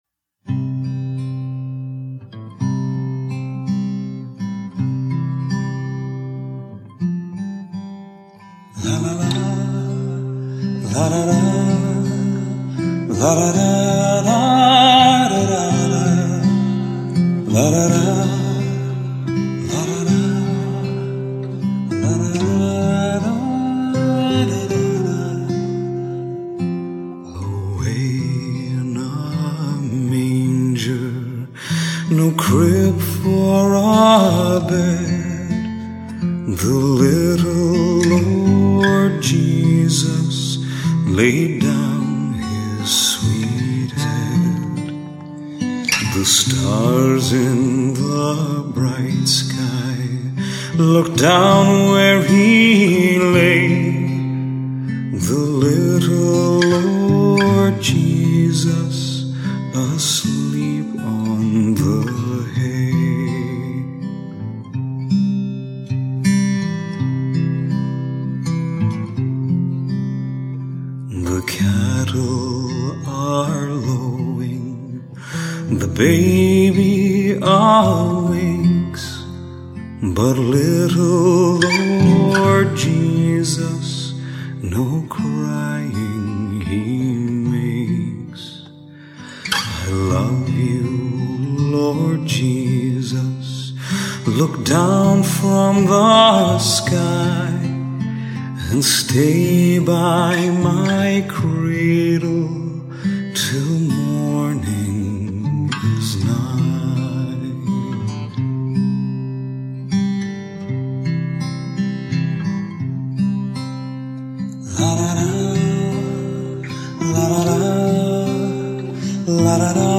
Ballad ala
Ml vx, gtrs Jesus sleeping Public Domain